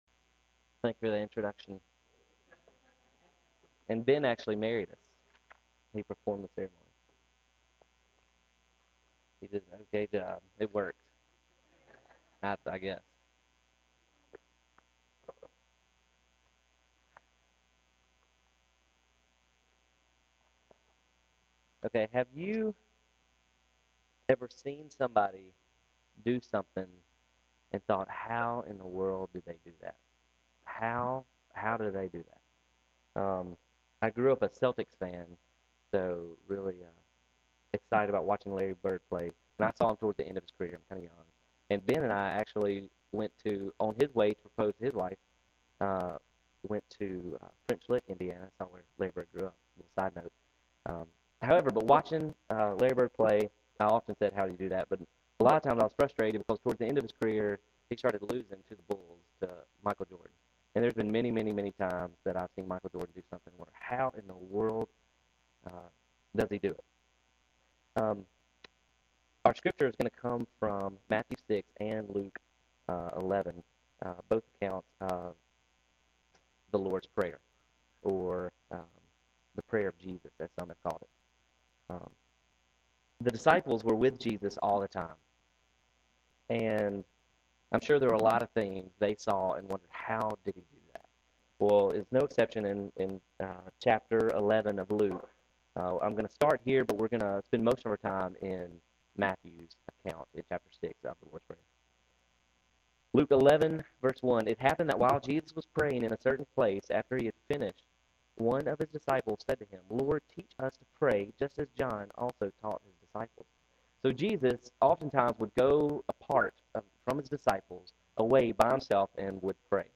November 15, 2009 PM Service